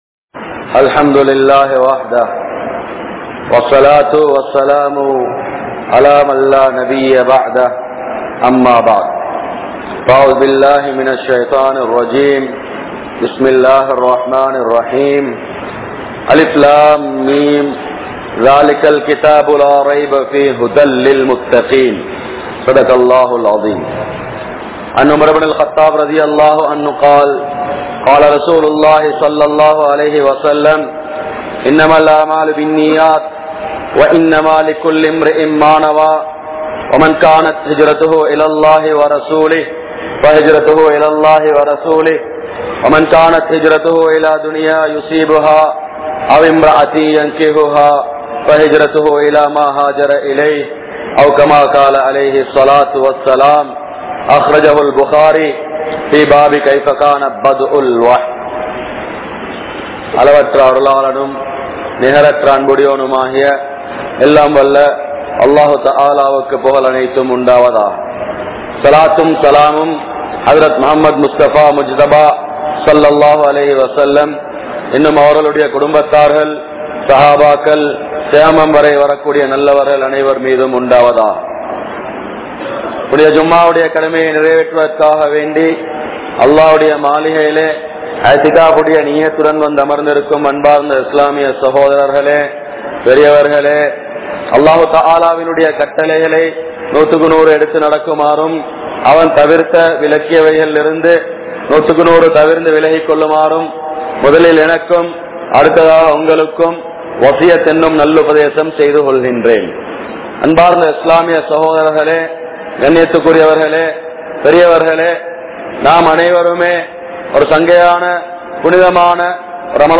Al Quran Oru Atputhamaahum (அல்குர்ஆன் ஒரு அற்புதமாகும்) | Audio Bayans | All Ceylon Muslim Youth Community | Addalaichenai